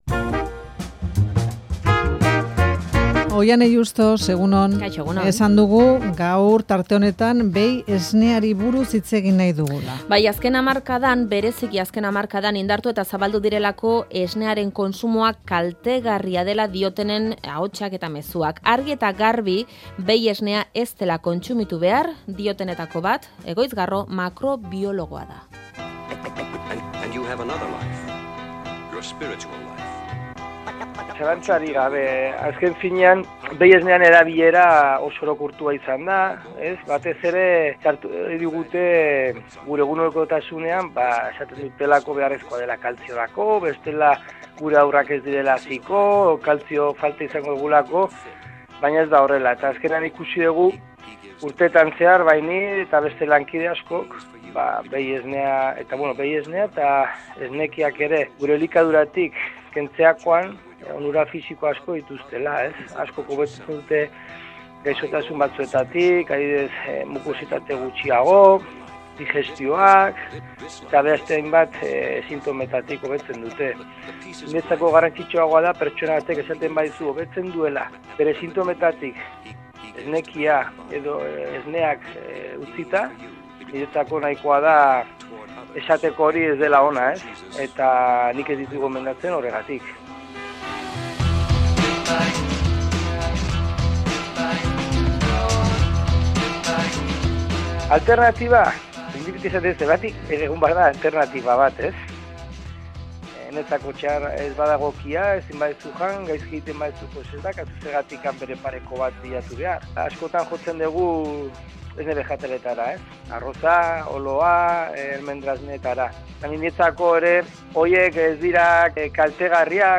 Behi esnearen kontsumoari buruzko eztabada, Faktoria, Euskadi Irratia